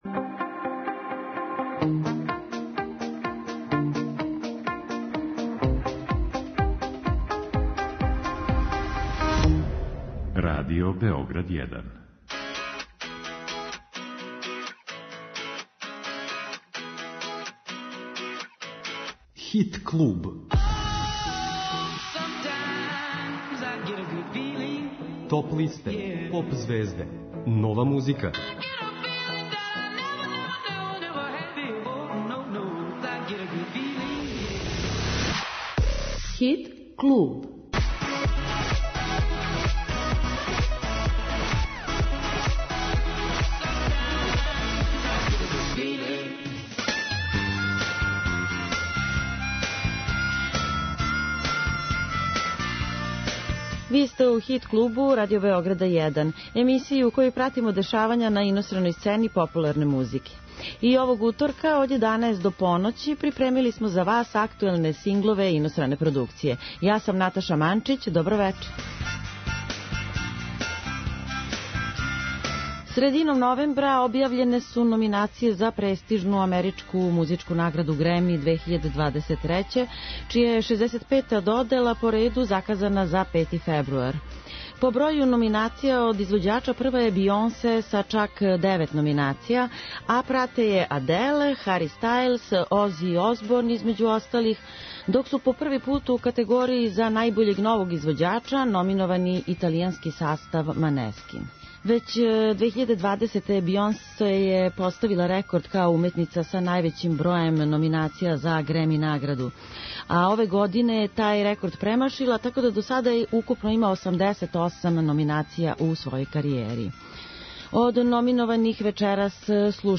У вечерашњем издању емисије припремили смо за вас номиноване за престижну америчку музичку награду GRAMMY 2023, чија је 65. додела по реду, заказана за 5.фебруар, као и остале актуелне хитове стране популарне музике.